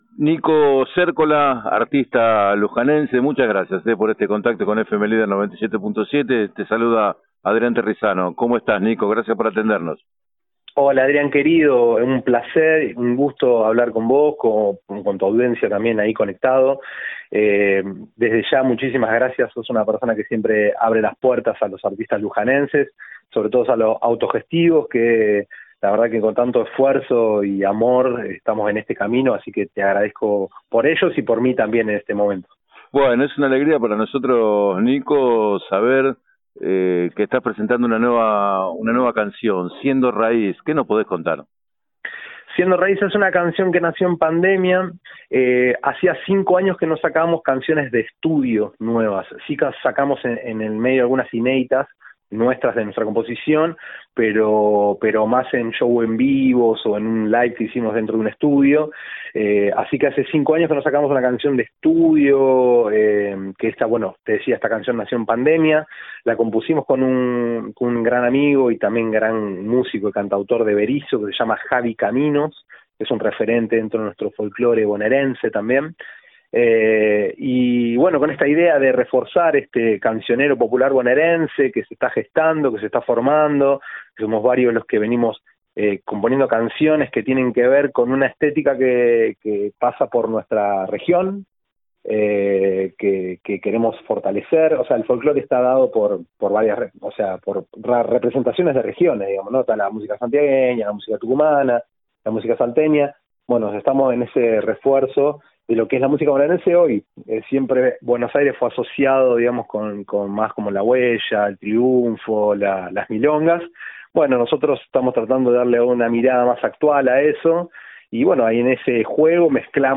En declaraciones al programa 7 a 9 de FM Líder 97.7